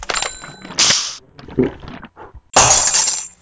perk_drink.wav